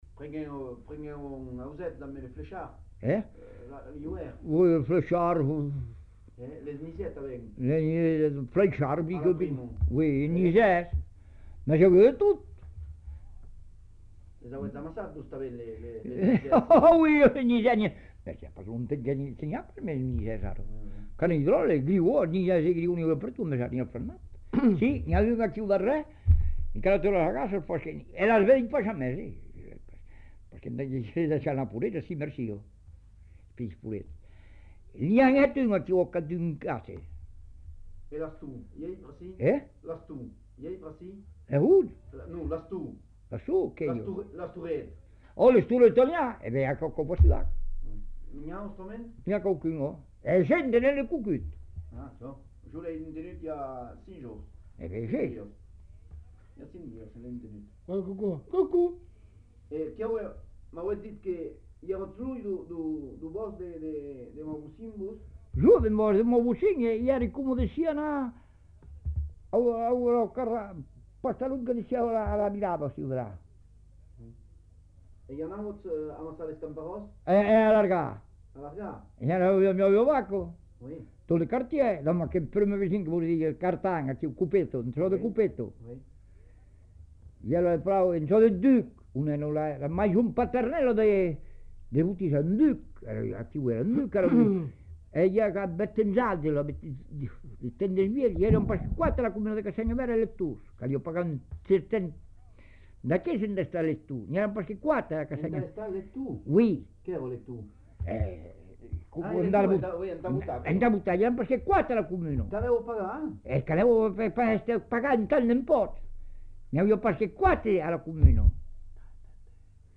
Genre : récit de vie